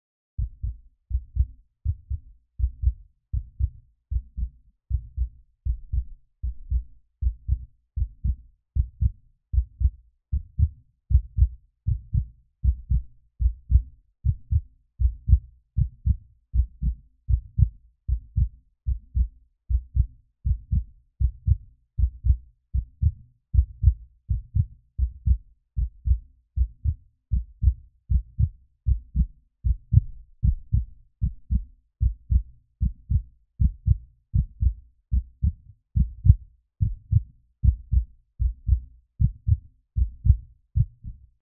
3D spatial surround sound "Heartbeat"
3D Spatial Sounds